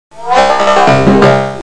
Descarga de Sonidos mp3 Gratis: robot 10.